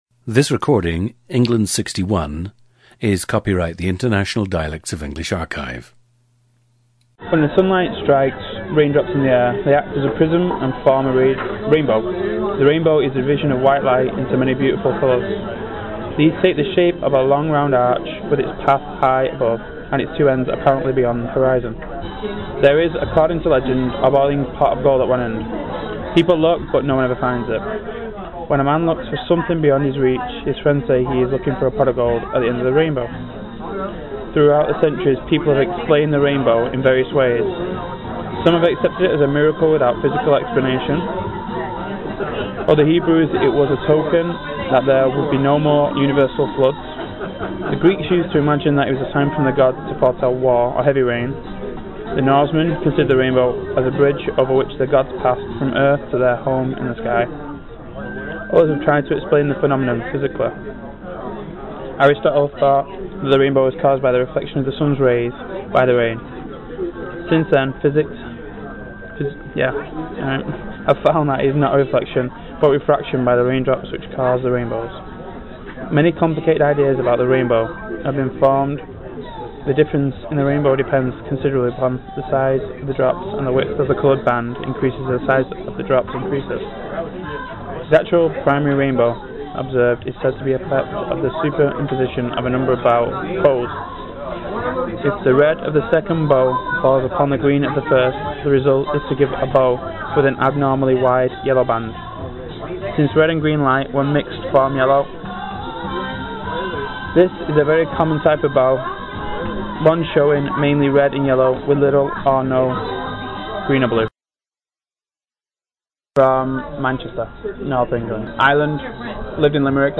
Listen to England 61, a 27-year-old man from Salford, near Manchester, in northwest England.
GENDER: male
• Recordings of accent/dialect speakers from the region you select.
The recordings average four minutes in length and feature both the reading of one of two standard passages, and some unscripted speech.